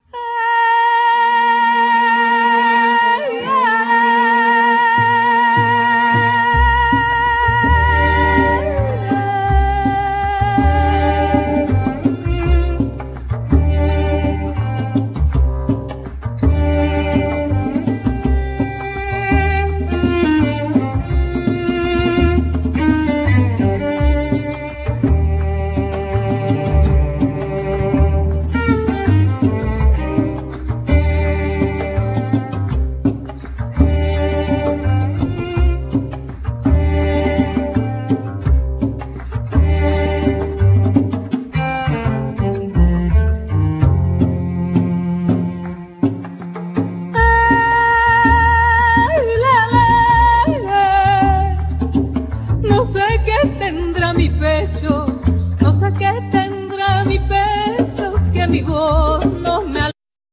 Voice, Maracas
Tambora, Llamador, Redoblante
Alegre, Platillos, Guache
Accoustic bass
Cello
Trombone